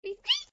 AV_rabbit_question.ogg